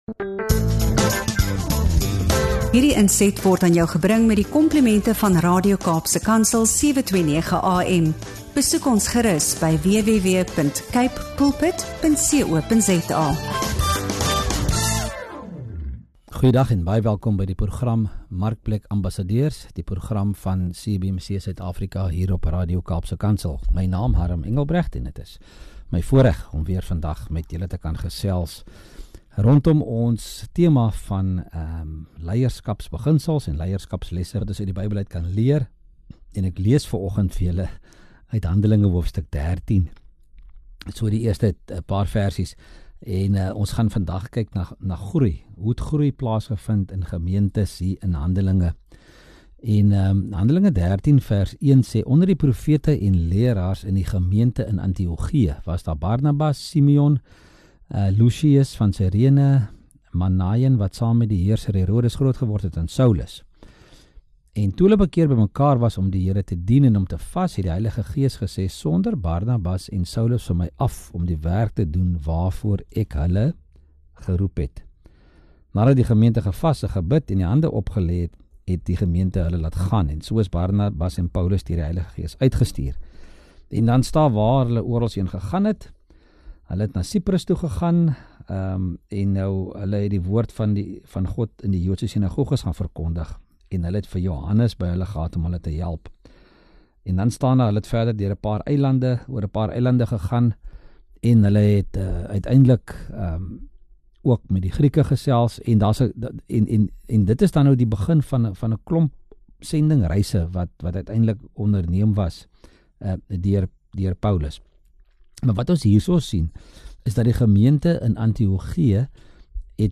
Ons kyk na hoe die gemeente in Antiochië gegroei het deur spanleierskap, uitstuur van leiers, sendingvisie en gehoorsaamheid aan die Heilige Gees. Die gesprek fokus op hoe ware groei plaasvind wanneer kerke en organisasies buite hulleself fokus, leiers oplei en die volgende generasie toerus. Ons ondersoek ook die rol van vrywilligers, waarom mense nie altyd betrokke raak nie, en hoe sterk leierskap vrywilligers motiveer om met oortuiging te dien.